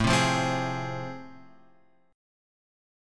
A Minor Major 7th Sharp 5th